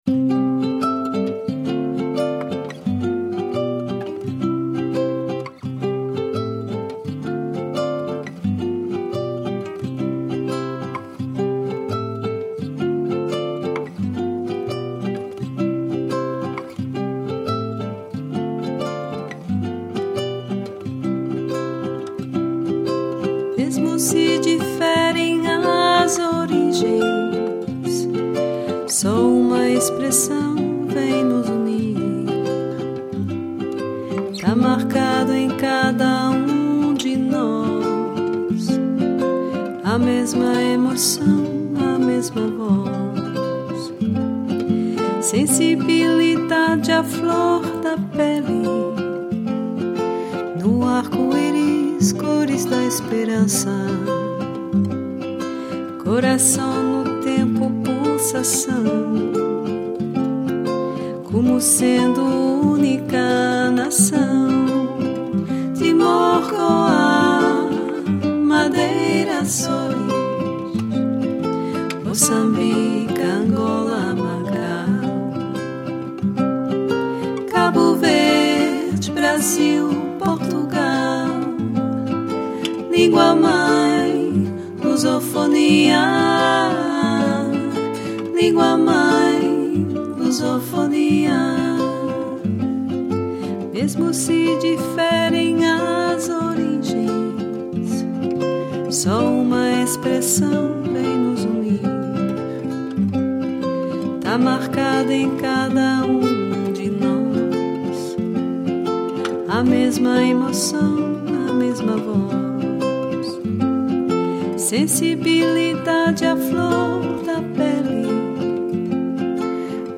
Genre: Future Jazz, Ambient, Downtempo.